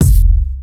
Wu-RZA-Kick 67.wav